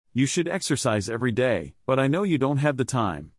Use a rising intonation and place stress on the two things you want to contrast.